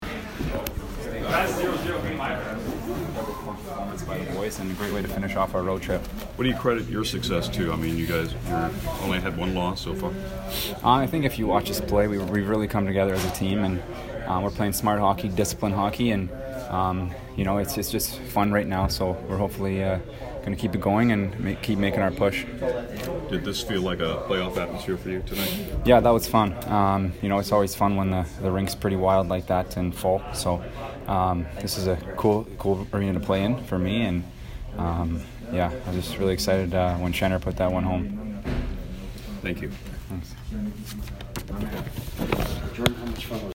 Jordan Binnington post-game 2/7